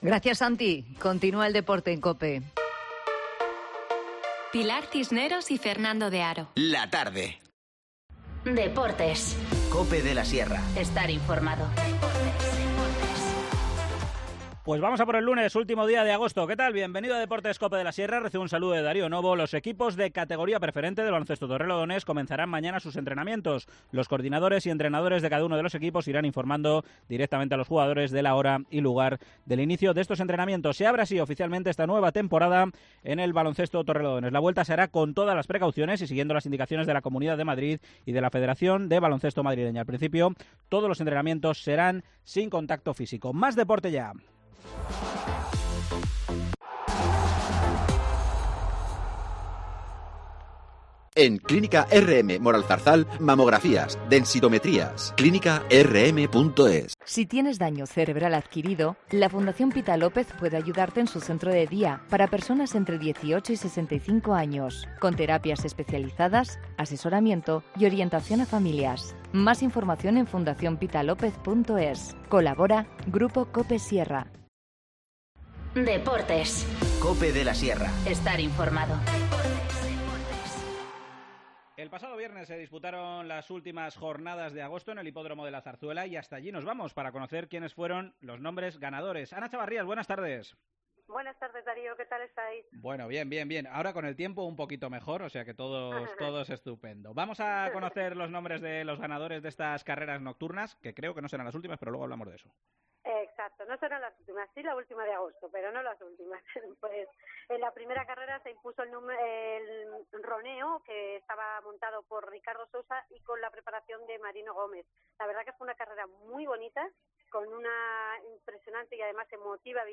Deportes local
Desde el Hipódromo de la Zarzuela